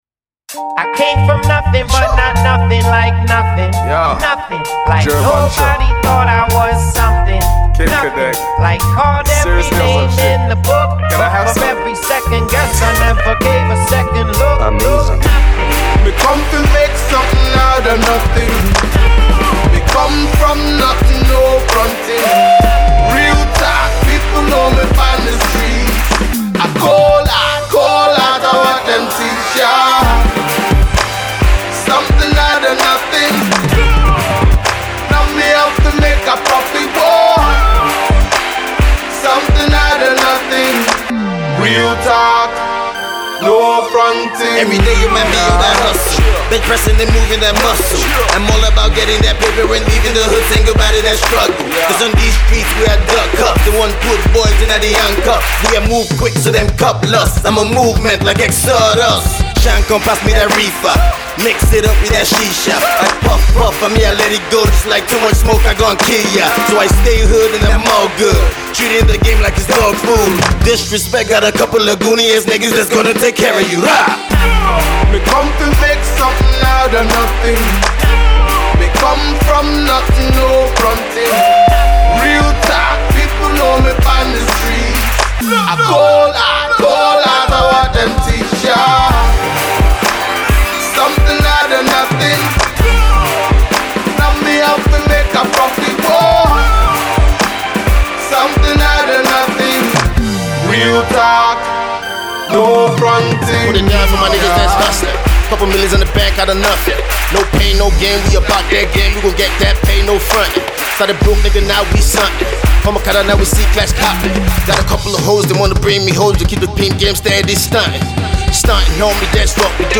dance hall
captivating beat and instruments
a very melodious hook